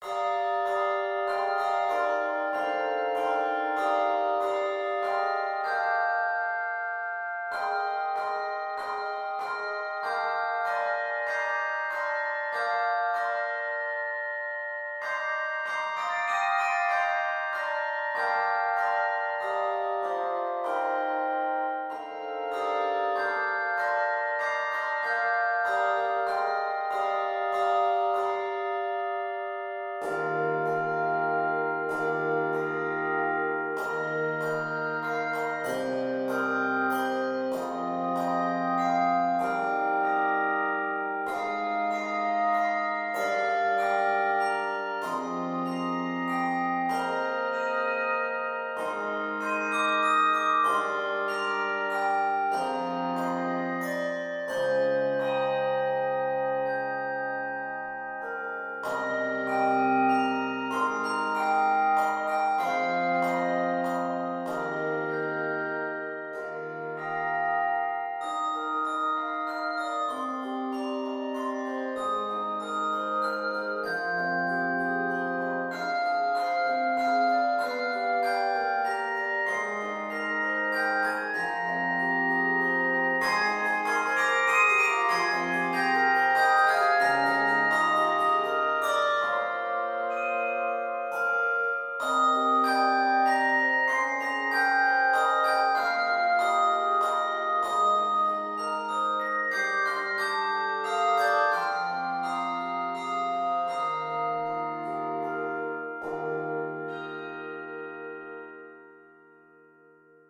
Key of Eb Major. 56 measures.